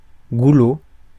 Ääntäminen
Synonyymit (slangi) bouche Ääntäminen France: IPA: [ɡu.lo] Haettu sana löytyi näillä lähdekielillä: ranska Käännös Substantiivit 1. цивка 2. шийка Suku: m .